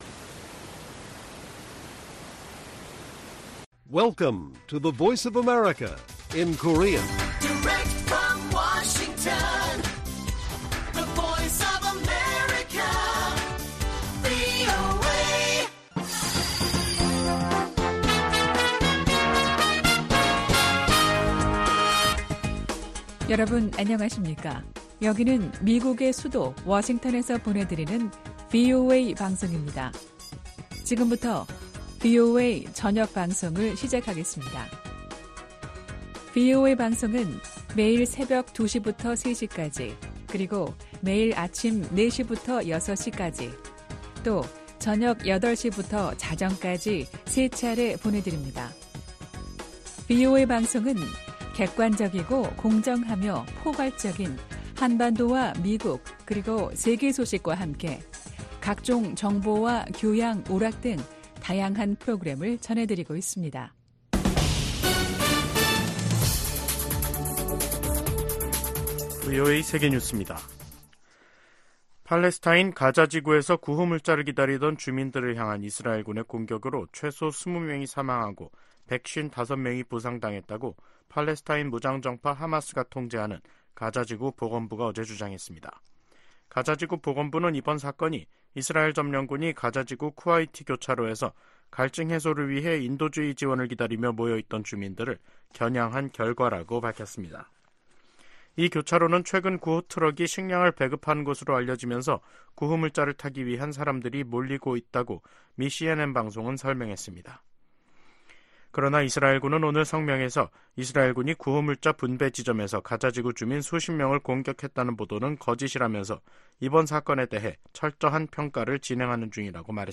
VOA 한국어 간판 뉴스 프로그램 '뉴스 투데이', 2024년 3월 15일 1부 방송입니다. 토니 블링컨 미 국무장관이 한국 주최 제3차 민주주의 정상회의 참석을 위해 서울을 방문합니다. 미 국방부는 한국의 우크라이나 포탄 지원 문제에 관해 우크라이나를 돕는 모든 동맹국을 지지한다는 원론적 입장을 밝혔습니다. 중국 내 탈북민 인권 보호를 위해 미국 정부가 적극적으로 나설 것을 촉구하는 결의안이 하원에서 발의됐습니다.